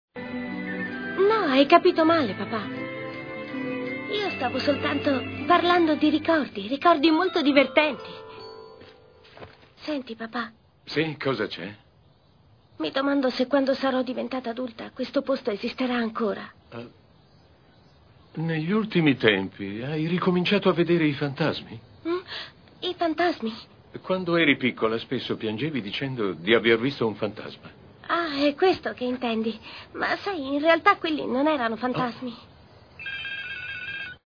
cartone animato
voceigio.mp3